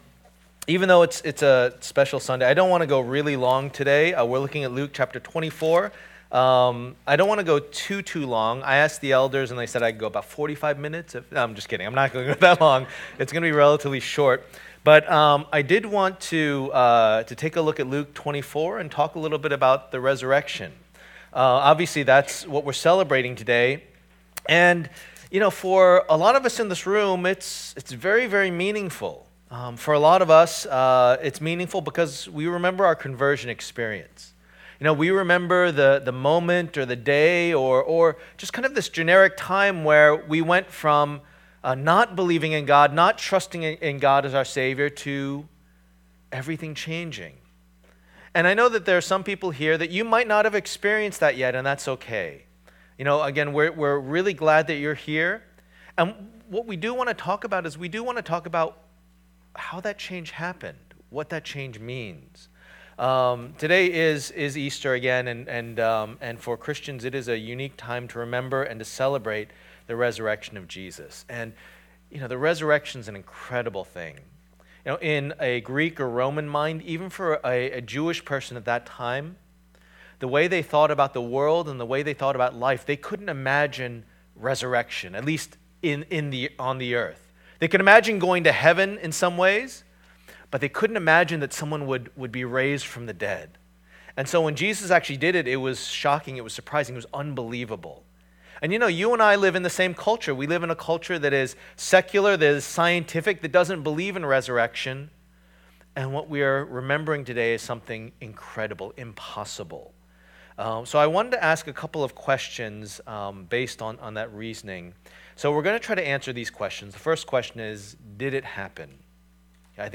The Messiah's Ministry Passage: Luke 24:36-49 Service Type: Lord's Day %todo_render% « Voices at the Cross Who Do You Say That I Am?